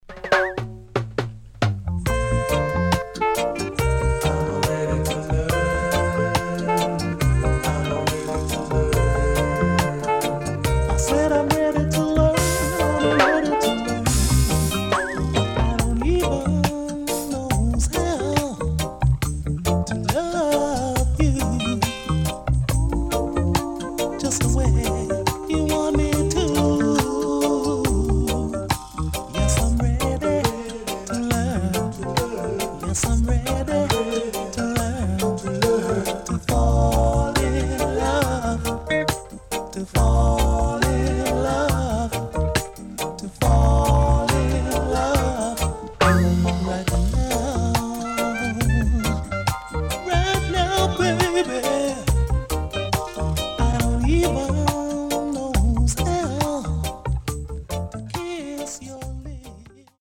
【12inch】
Nice Lovers Vocal.W-Side Good
SIDE AA:少しノイズ入りますが良好です。